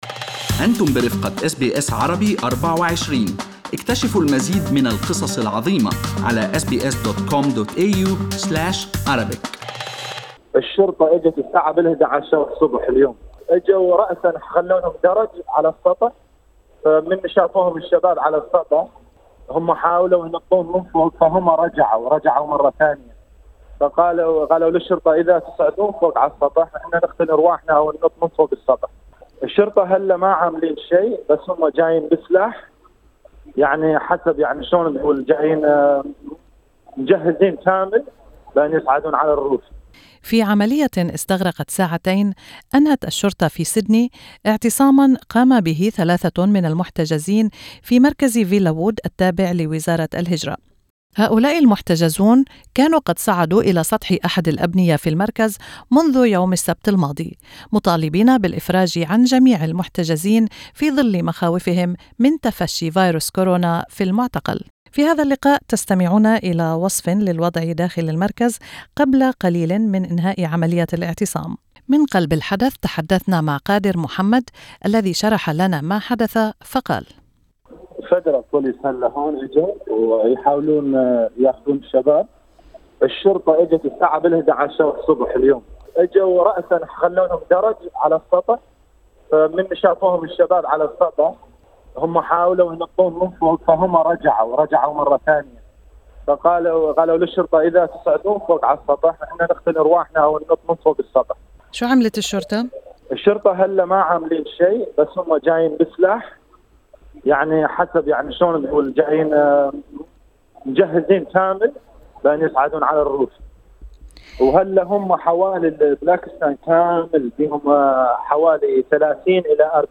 محتجز في مركز فيلاوود يصف دخول الشرطة لإنهاء اعتصام ثلاثة محتجزين على سطح المركز